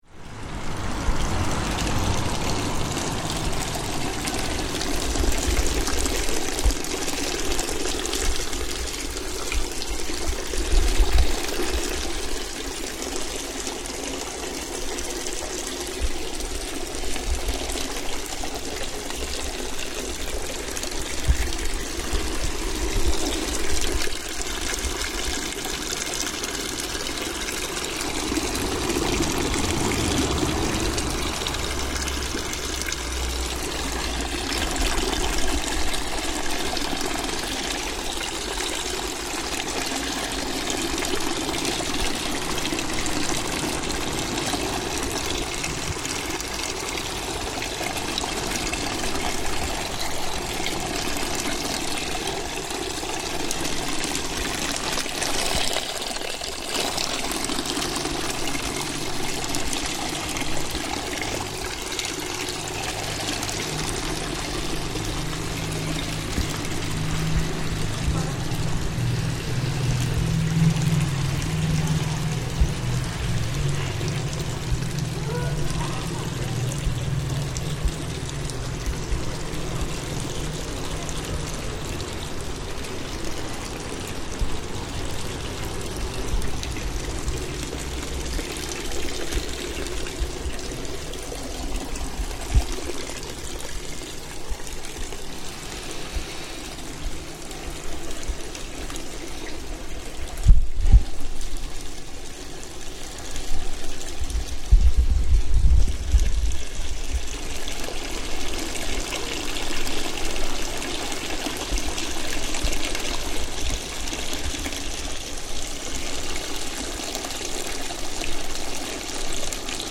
Microphone moving around the fountain
The "fountain of three faces" in Treviso, Italy has water coming from a three-faced figure on one head - here we move around the fountain to listen to it from different angles.